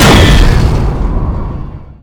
bladeslice1.wav